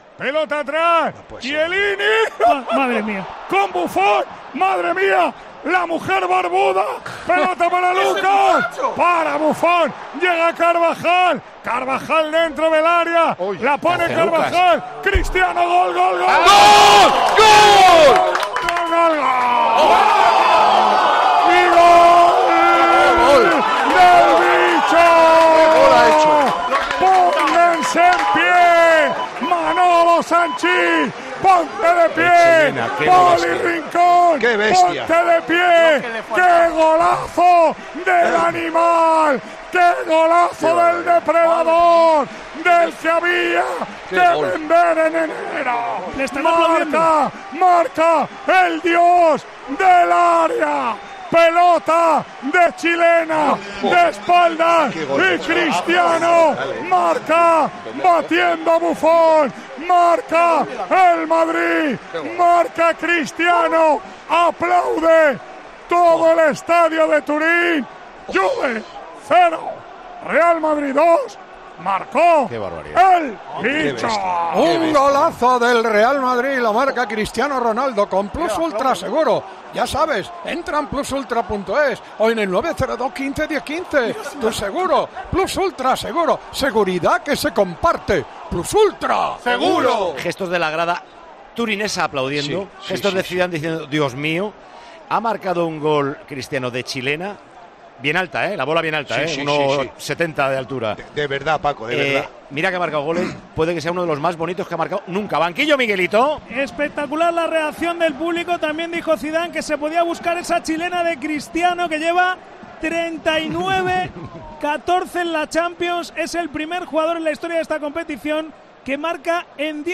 Así narró Lama el gol de chilena de Ronaldo